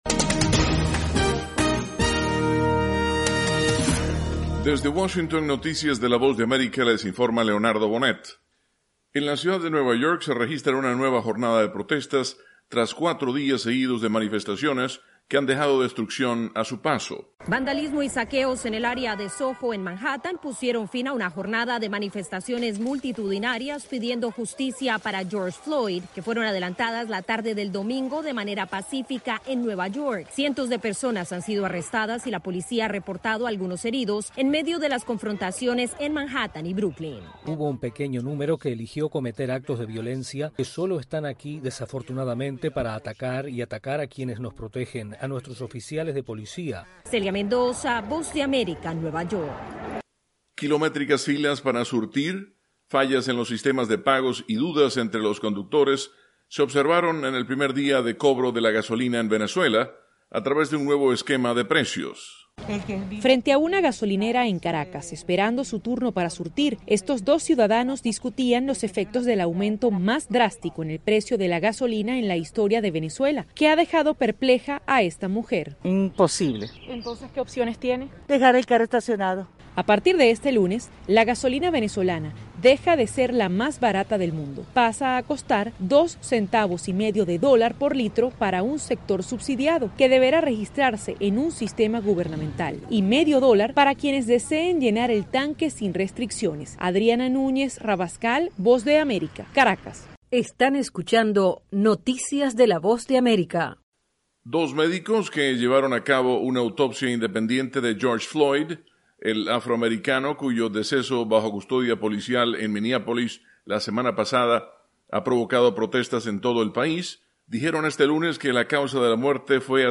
Segmento de 3 minutos de noticias